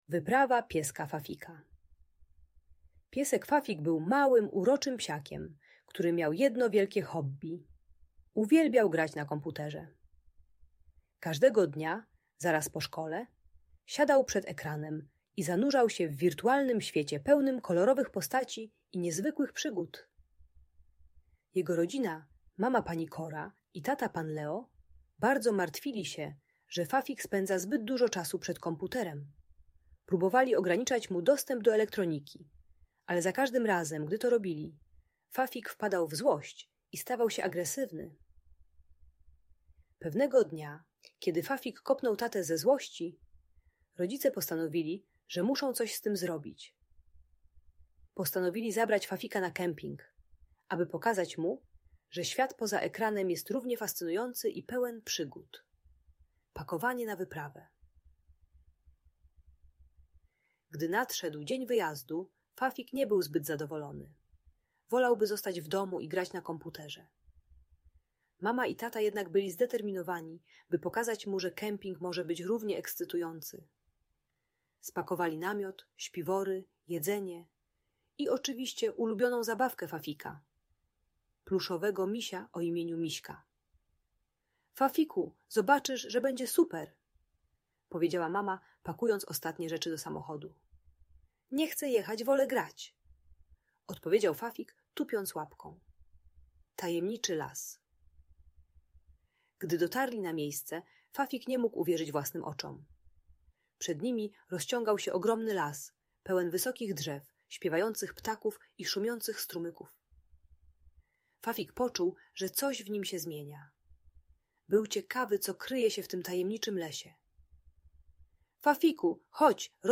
Wyprawa Pieska Fafika - Audiobajka